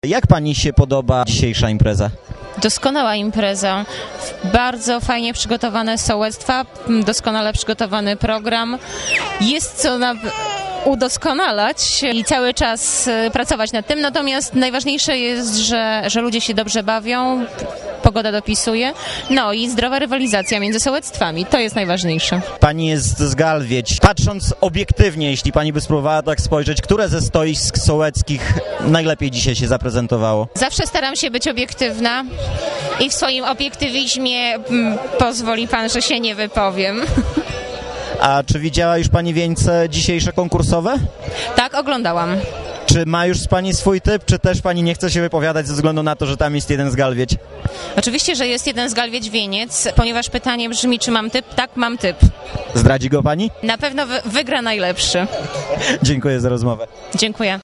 mówi Ewa Bogdanowicz-Kordjak, wicestarosta gołdapski